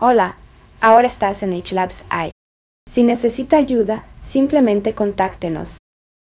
Generate natural, multilingual speech with customizable voices, clean audio refinement, and real-time-ready performance for narration, videos, and live use.
voice-translate-output.wav